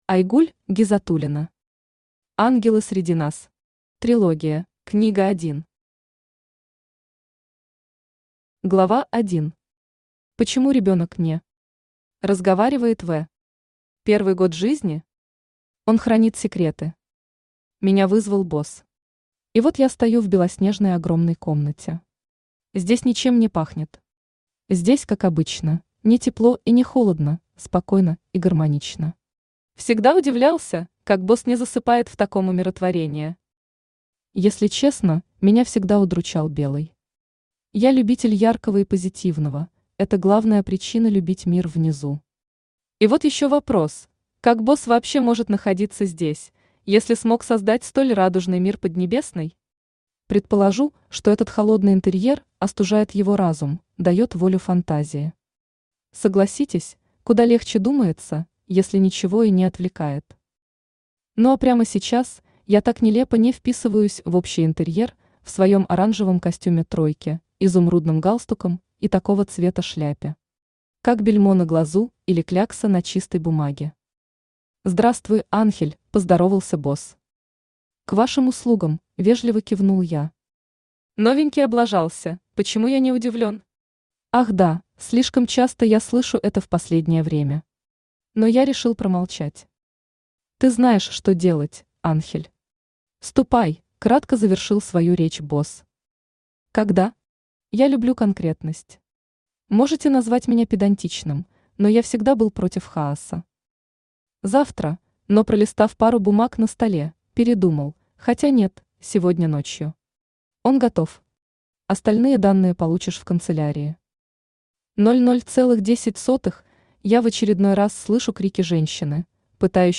Aудиокнига Ангелы среди нас. Трилогия Автор Айгуль Айратовна Гизатуллина Читает аудиокнигу Авточтец ЛитРес.